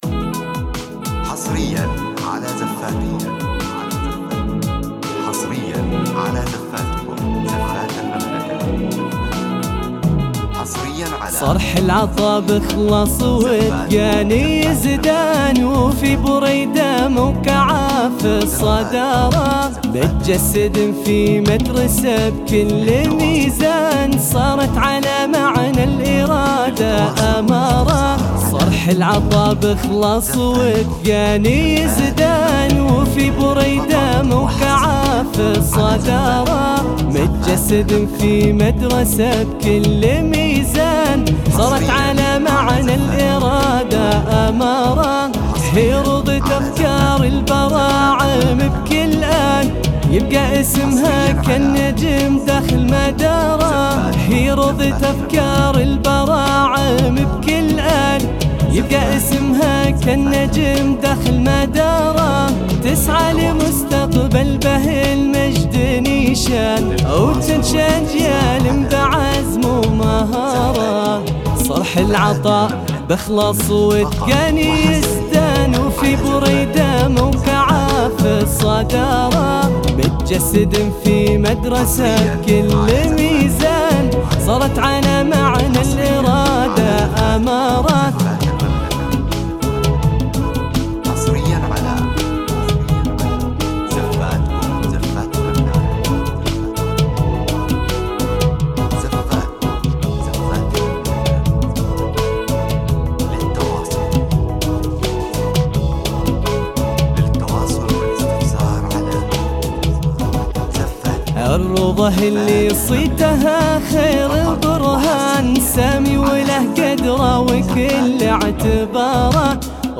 • بدون موسيقى، مناسب للمدارس والفعاليات الإسلامية.
• توزيع وتنسيق احترافي.